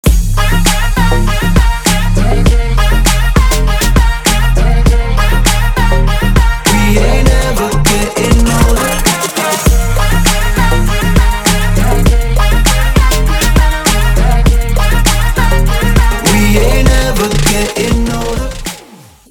• Качество: 320, Stereo
поп
мужской вокал
веселые
dance
EDM
Trap
future bass